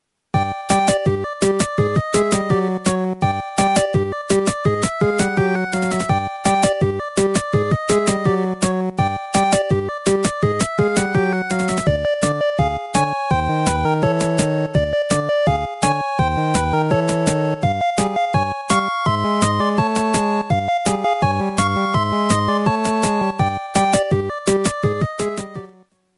視聴音階がちょっとマヌケな感じ。